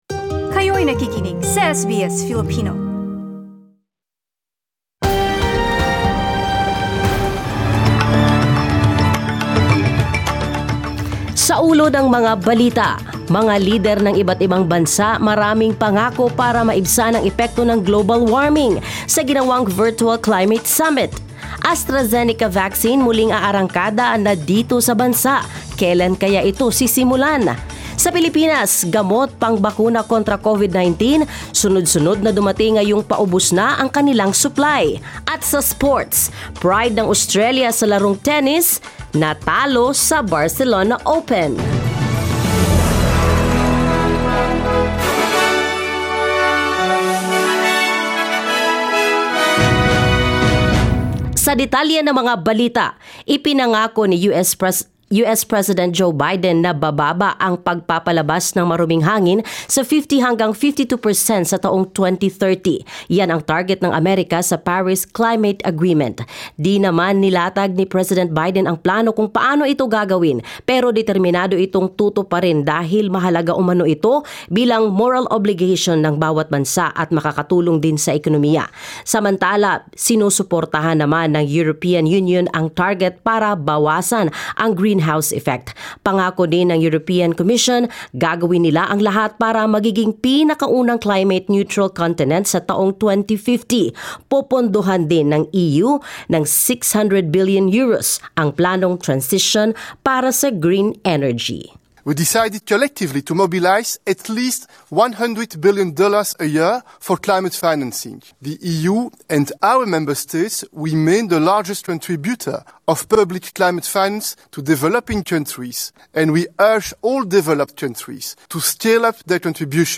SBS News in Filipino, Friday 23 April